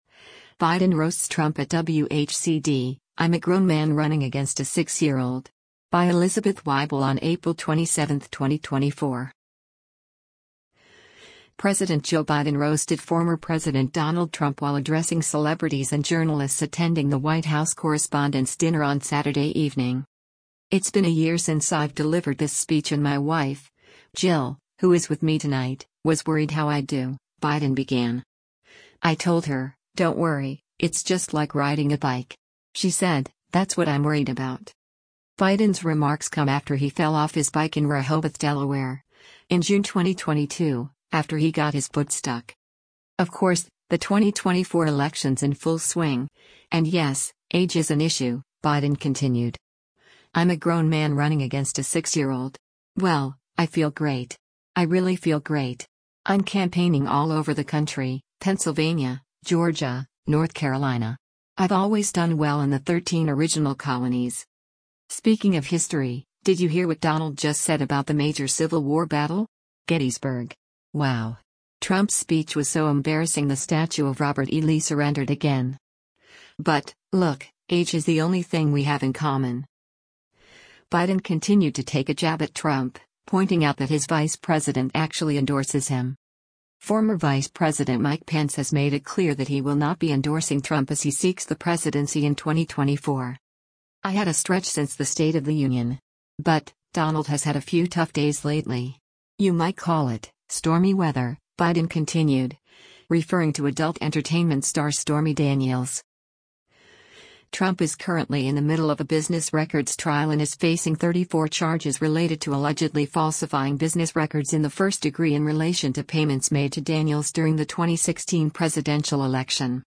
President Joe Biden roasted former President Donald Trump while addressing celebrities and journalists attending the White House Correspondents Dinner on Saturday evening.